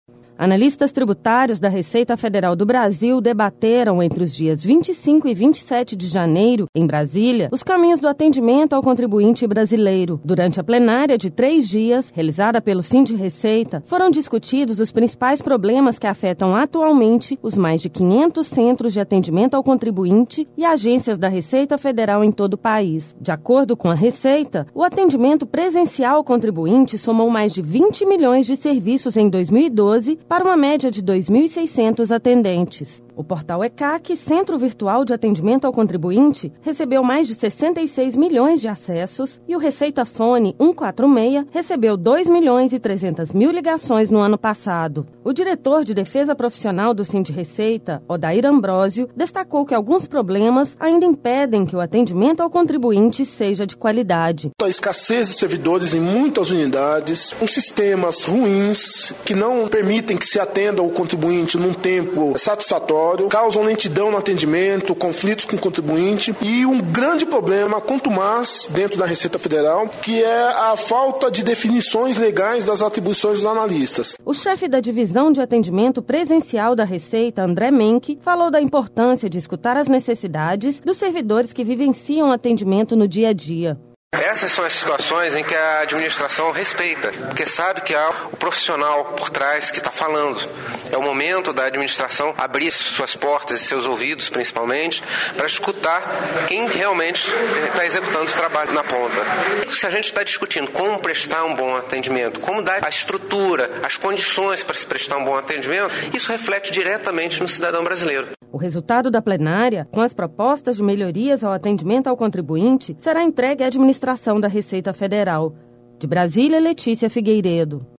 Matéria-Plenária-de-Atendimento.mp3